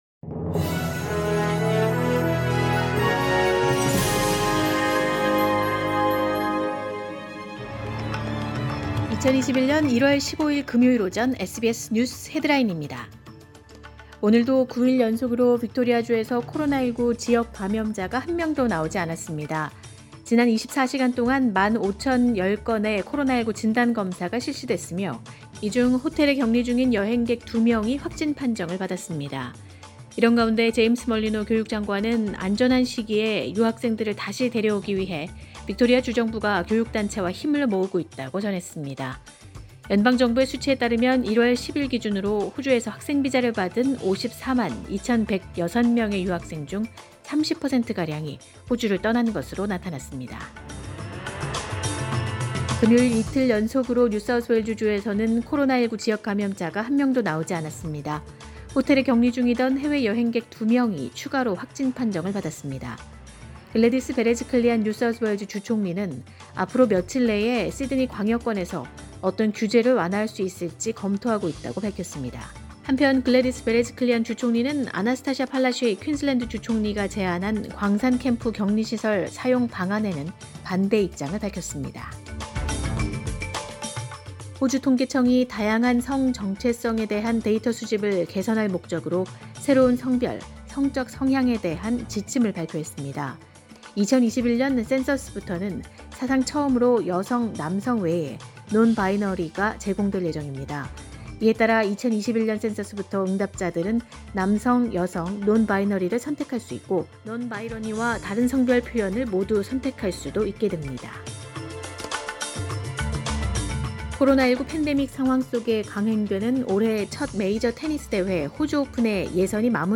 2021년 1월 15일 금요일 오전의 SBS 뉴스 헤드라인입니다.